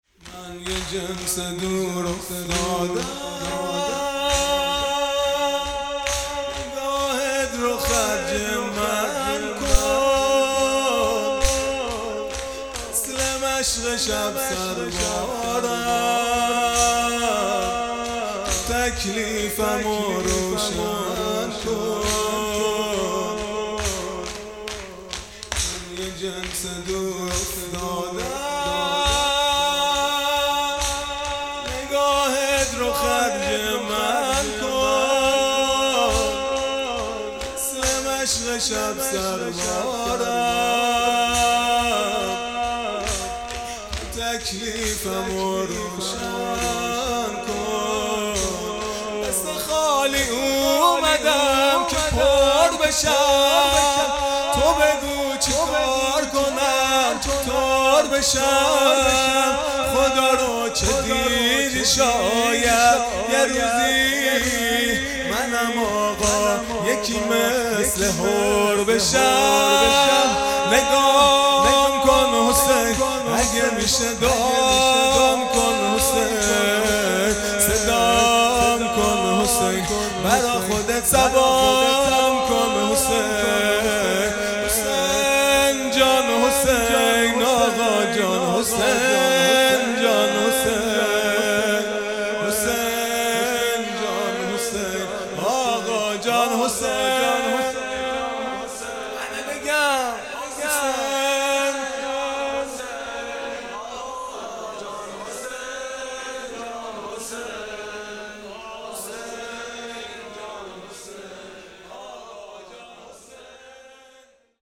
خیمه گاه - هیئت بچه های فاطمه (س) - شور | من یه جنس دور افتاده ام | پنج شنبه ۲۵ دی ۹۹